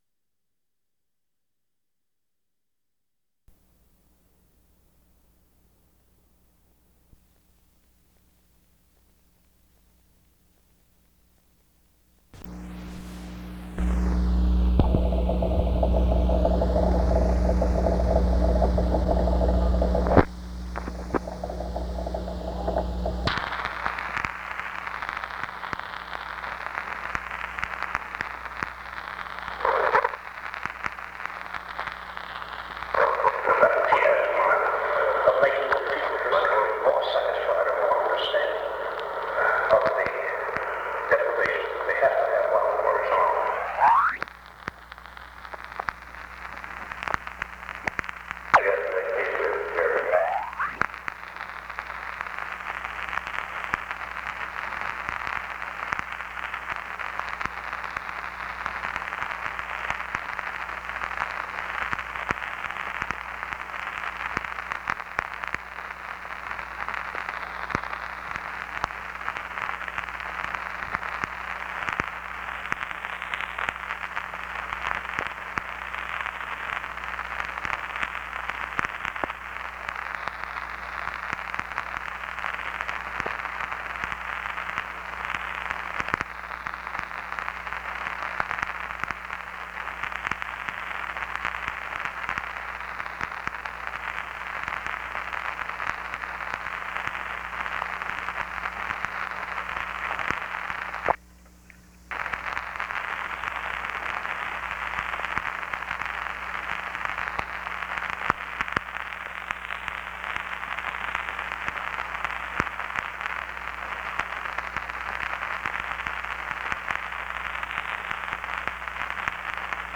Secret White House Tapes | Harry S. Truman Presidency